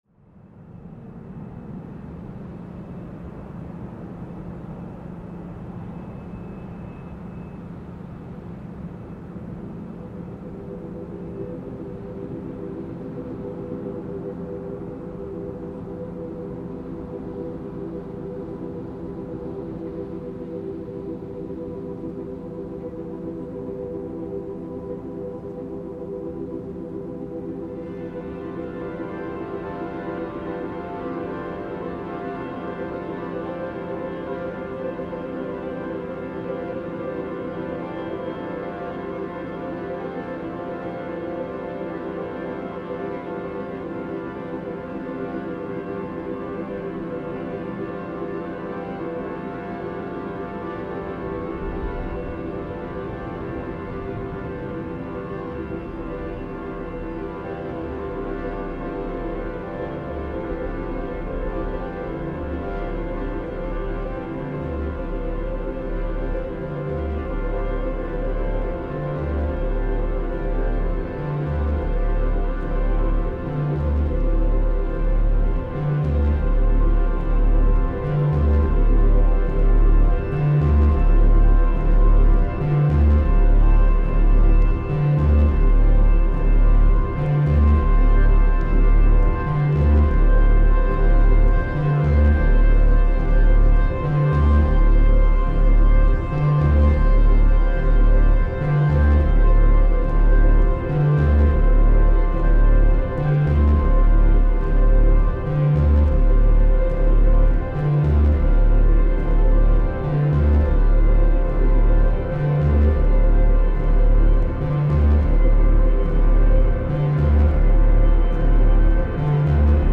Airfield traffic at Gatwick Airport reimagined